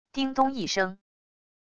叮咚一声――wav音频